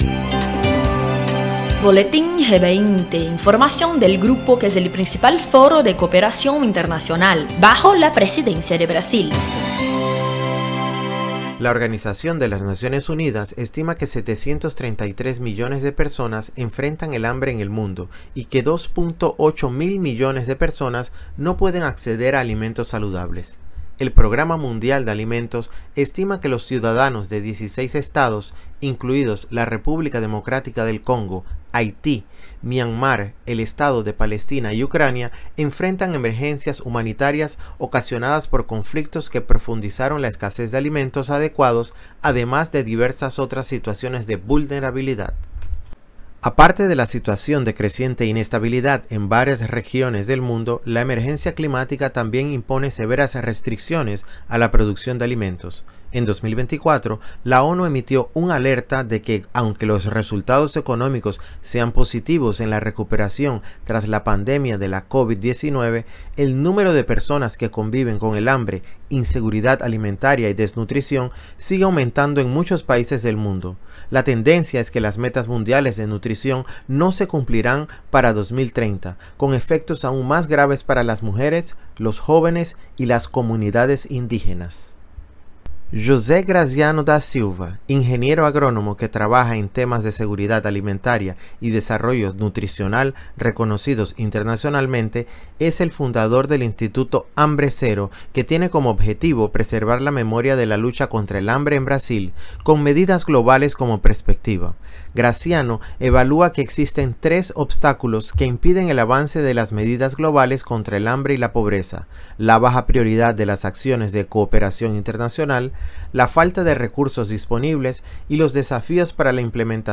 Las sólidas medidas internacionales para combatir el hambre enfrentan desafíos como la falta de inversión. Las múltiples crisis globales también son un obstáculo, pero las soluciones pueden venir de la sociedad civil. Escucha el reportaje especial e infórmate más.